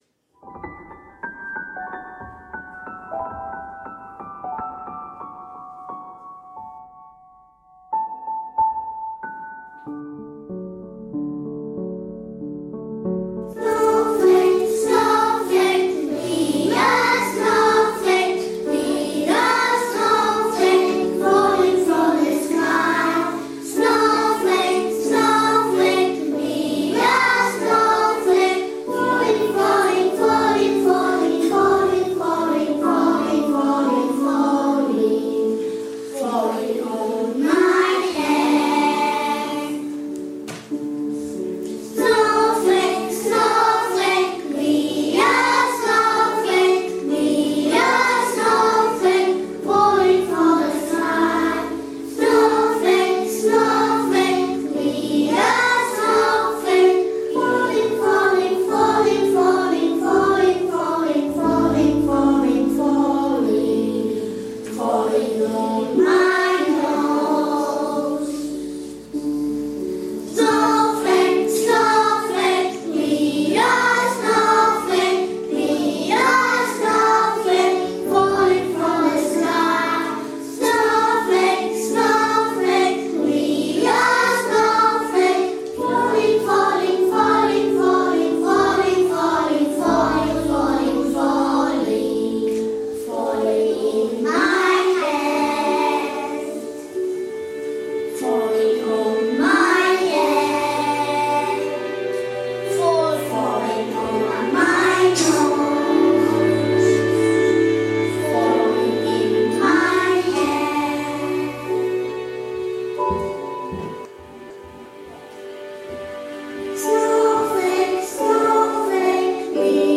Els alumnes de 1r van cantar una cançó preciosa, preciosa LITTLE SNOWFLAKE; petit
floquet de neu, amb les seves veus d’àngels la van brodar!!!!!!